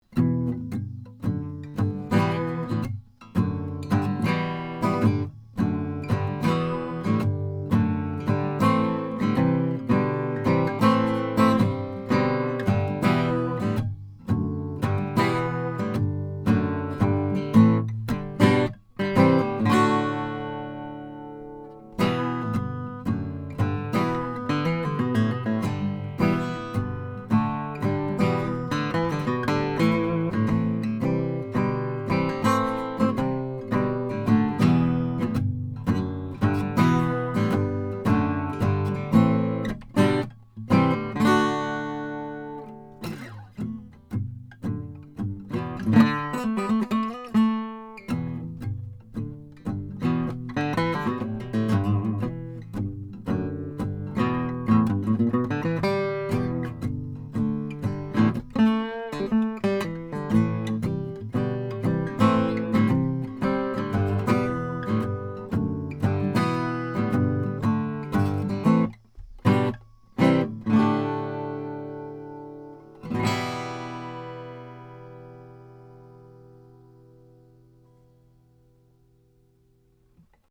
The sound is big, with some nice bass heft, and the trebles are smooth and silky.
These 16 MP3s are recorded through a spaced pair of Schoeps CMT 541 condenser mics into a pair Black Lion Audio B173 premps using a Metric Halo ULN8 interface, with MP3s made in Logic. These files have no compression, EQ or reverb -- just straight signal.
1933 MARTIN O-17 GUITAR